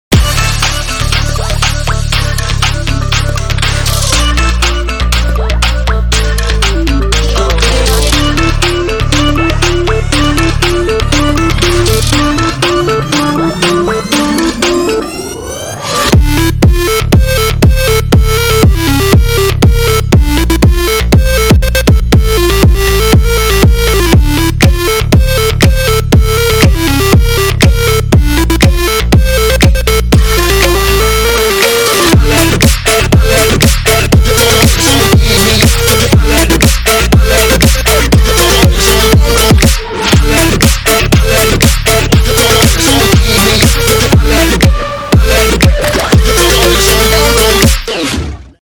dance electronic instrumental
BPM: 120
recreated your audio file at a higher quality for you~